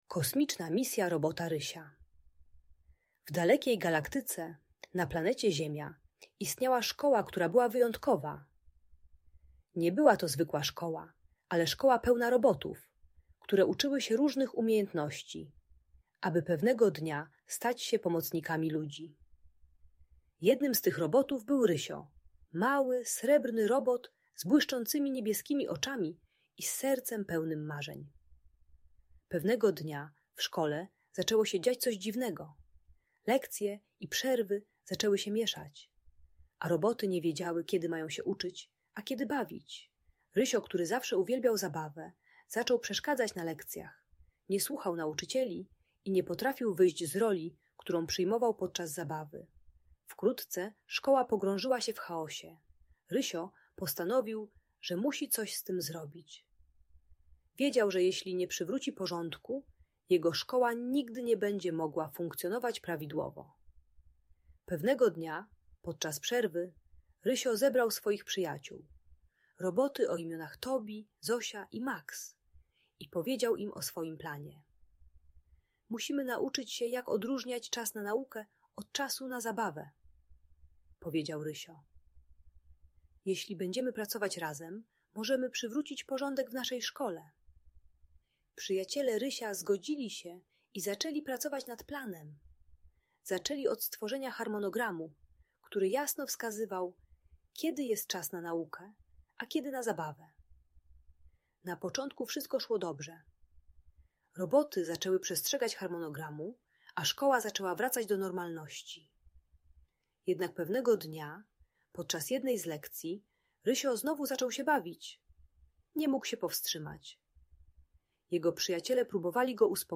Kosmiczna Misja Robota Rysia - Audiobajka